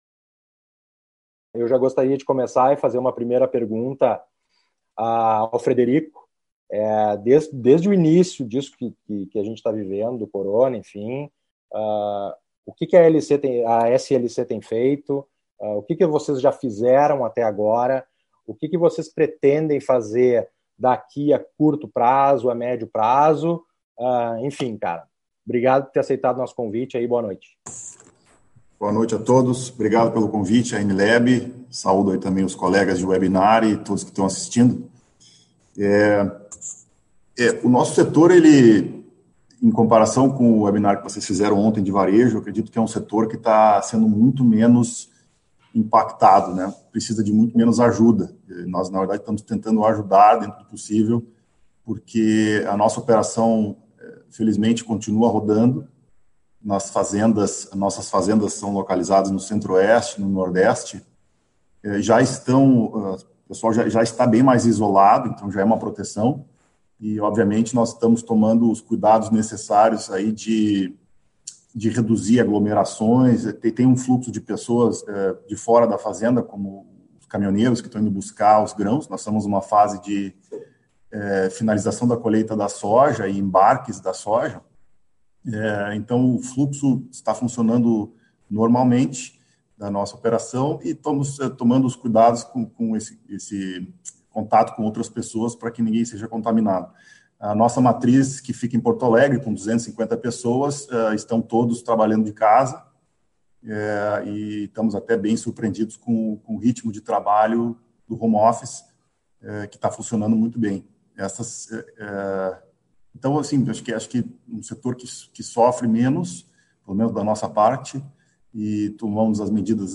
A AnLab e a Comissão de Inovação da Farsul (Federação de Agricultura do Estado do Rio Grande do Sul) realizaram webinar sobre os rumos do Agro em tempos de pandemia. Exibido ao vivo pelo Notícias Agrícolas